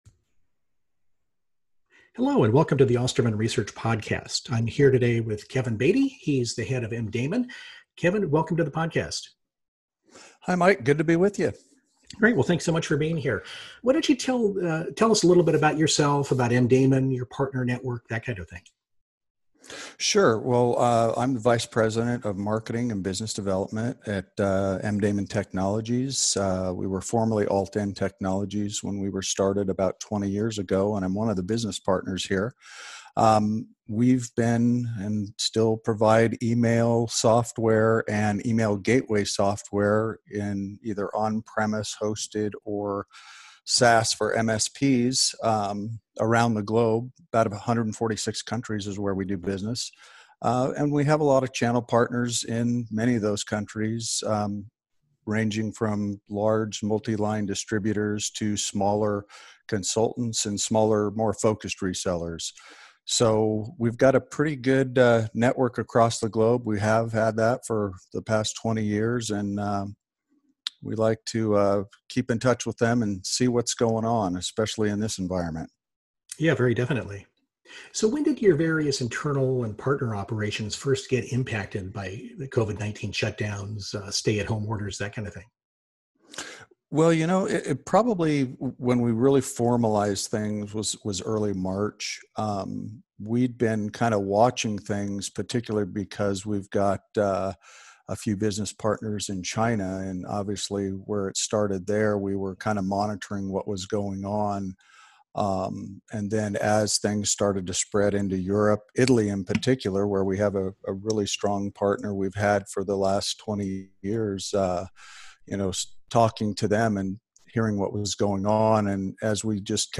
A Conversation About the Challenges Presented by the COVID-19 Crisis